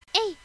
なんとなくメイド特集開催中♪いろんなメイド声がありますよ〜。
----以下は「戦うメイドさん」！格闘ゲームに使えるかも？----
fightmaid_03.mp3